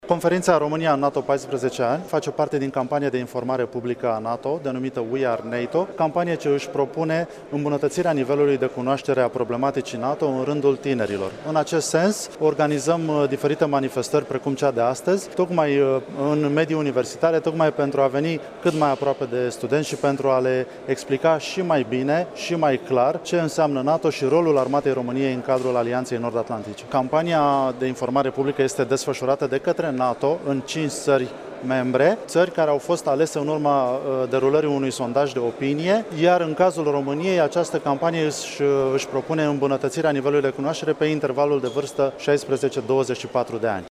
Universitatea Alexandru Ioan Cuza din Iaşi a găzduit, astăzi, conferinţa cu tema „România în NATO – 14 ani”. Evenimentul se constituie într-o campanie de informare publică organizată de Alianţa Nord Atlantică.